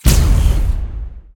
Erekir unit SFX
pulseBlast.ogg